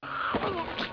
At 8:46 in the episode, Tasha gets tangled up in the clothes line and falls to the ground. As she does, a male voice goes "oof!"